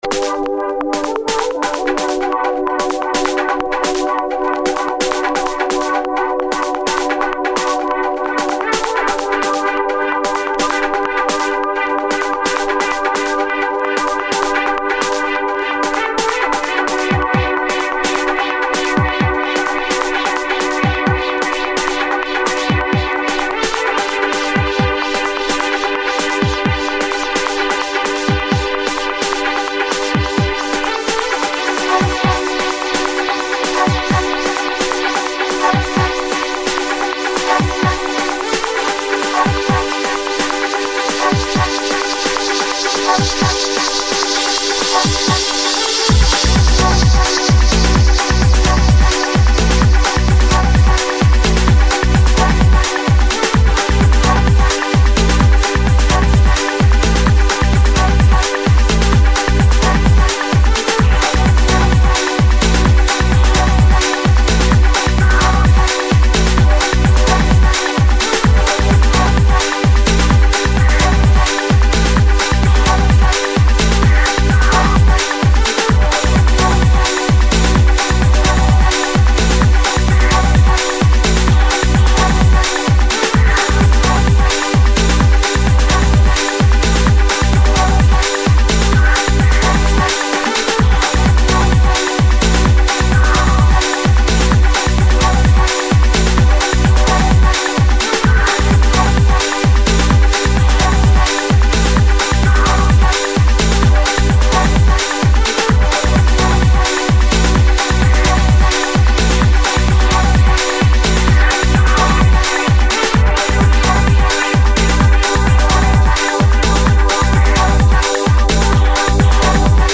Список файлов рубрики ¤Super / Club / Dance¤
Таги: mix